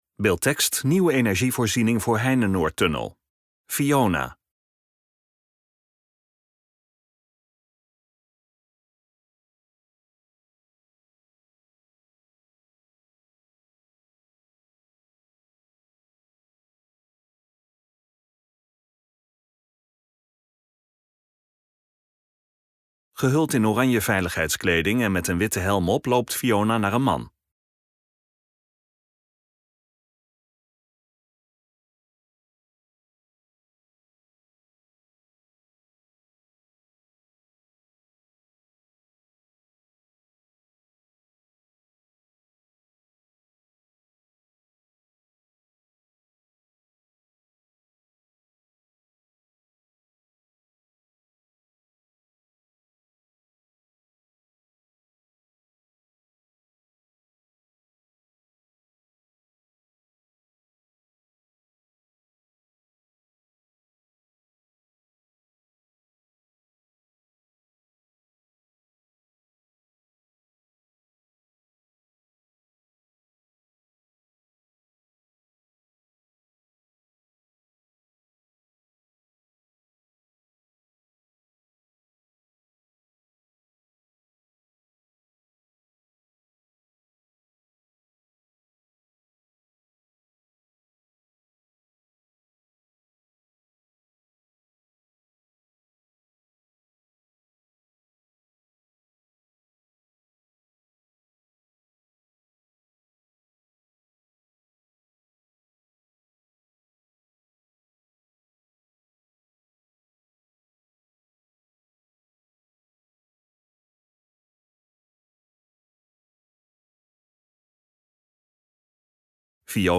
OPGETOGEN MUZIEK
LEVENDIGE MUZIEK